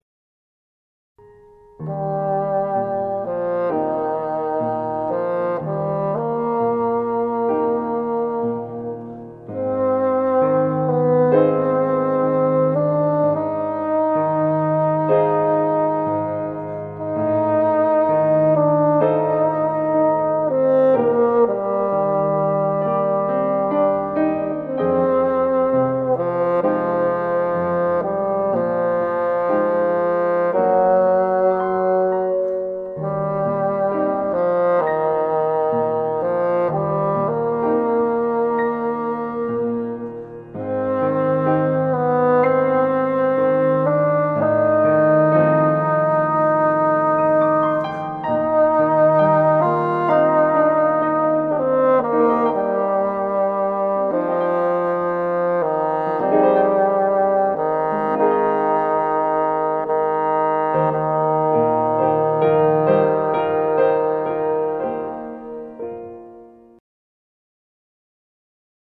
癒しの音楽